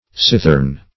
Cithern \Cith"ern\, n.